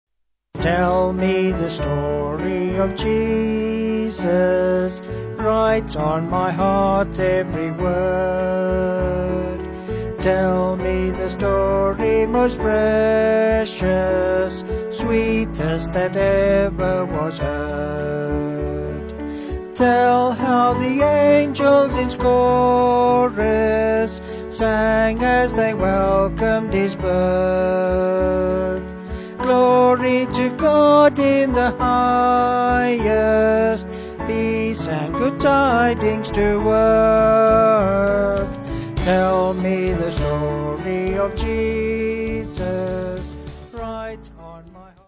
8.7.8.7.D with refrain
Vocals and Band